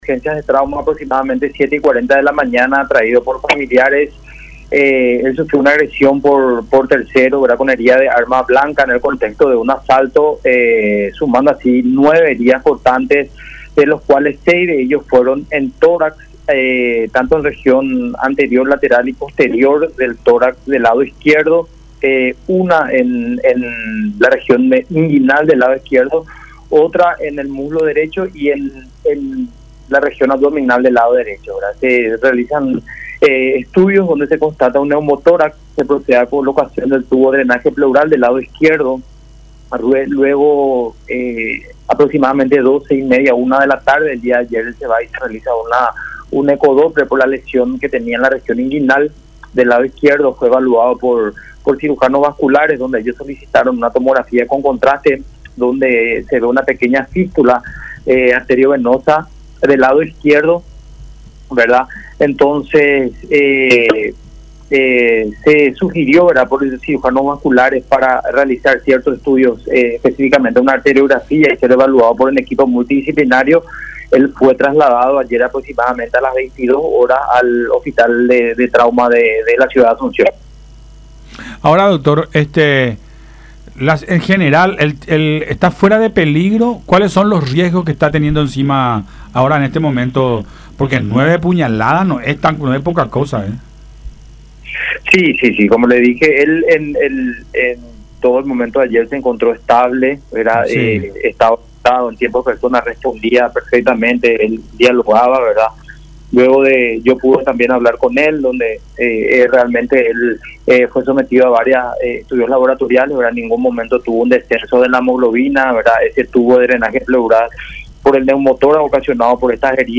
en diálogo con Nuestra Mañana por Unión y radio La Unión.